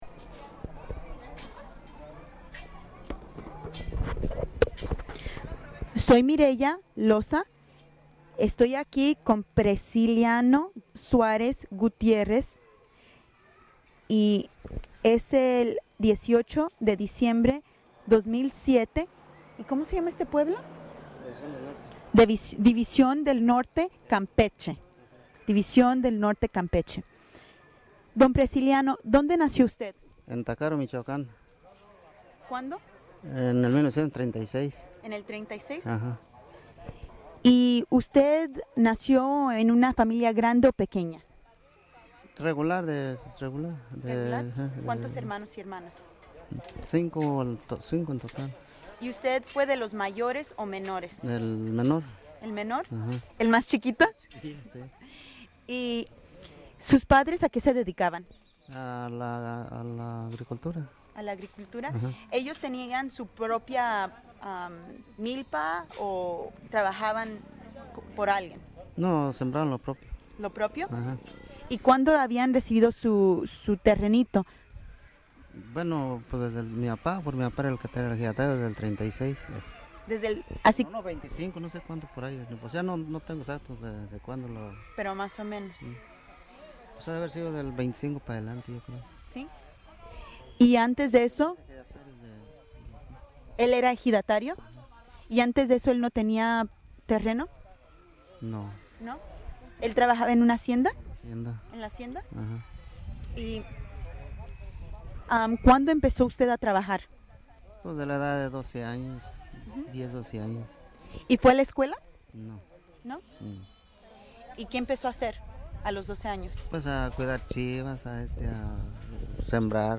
Location División del Norte, Campeche